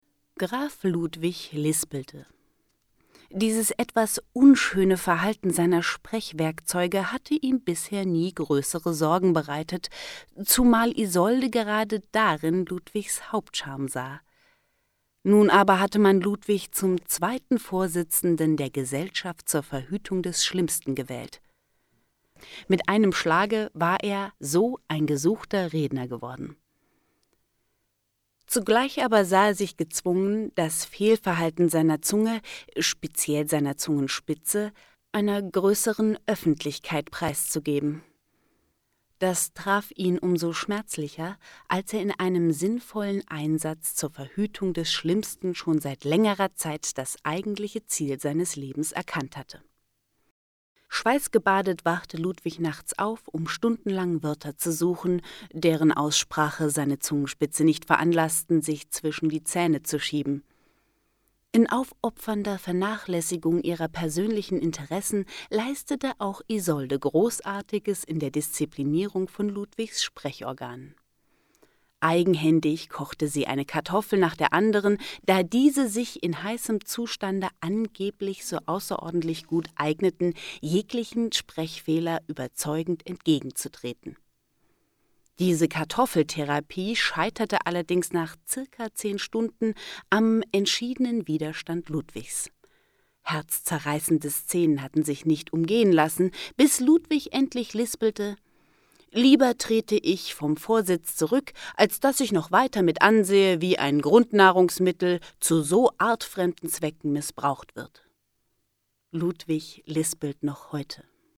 Neue junge Stimme, gut für Hörspiele und Computerspiele geeignet.
Sprechprobe: Industrie (Muttersprache):